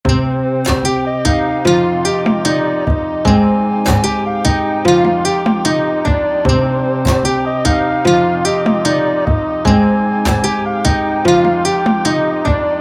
#4 Beat Making Plugin für Melodie & Lead-Sounds
• Enthält Synthesizer, Keys, Gitarre, Expansions, Effekte & mehr
02_komplete_13_instrumente.mp3